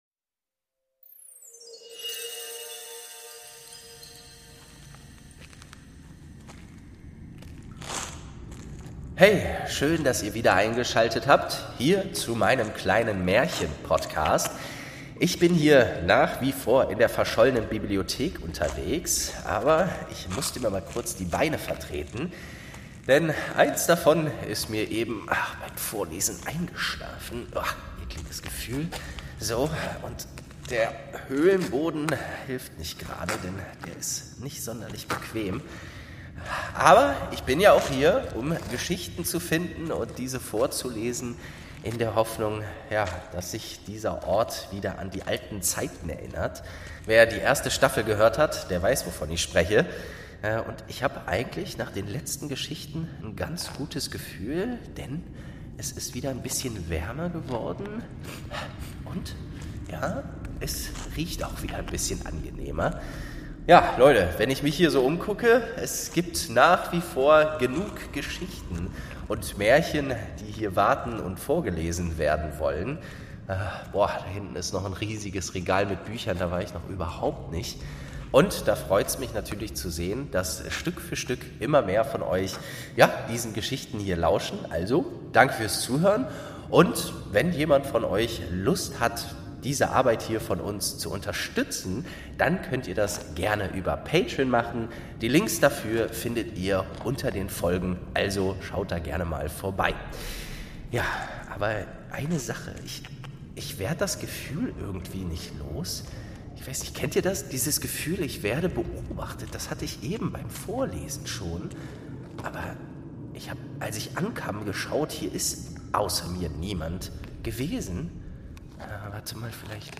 5. Schneewittchen | Staffel 2 ~ Märchen aus der verschollenen Bibliothek - Ein Hörspiel Podcast